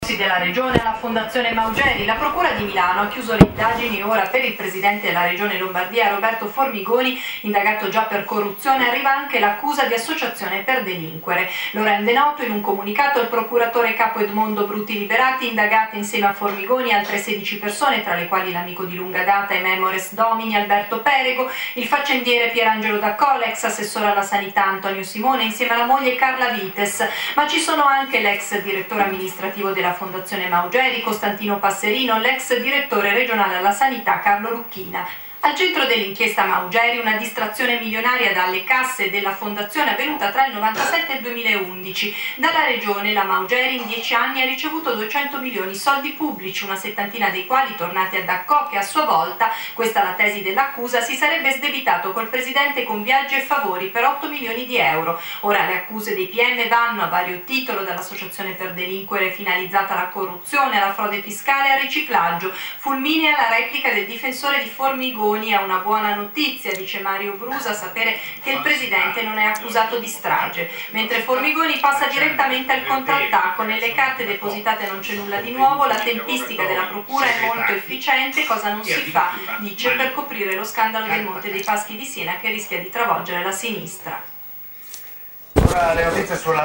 vai all'audio tratto dal TG3 Regione del 12 febbraio ore 19 e 40: DM450664